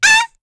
Lavril-Vox_Damage_kr_02.wav